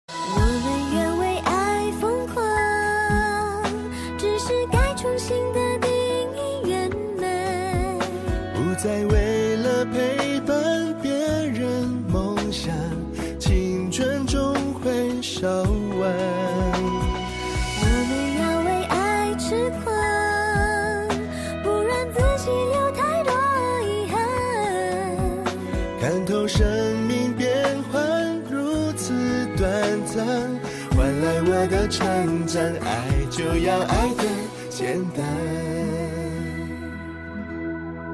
M4R铃声, MP3铃声, 华语歌曲 113 首发日期：2018-05-15 14:44 星期二